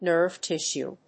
NERVE+TISSUE.mp3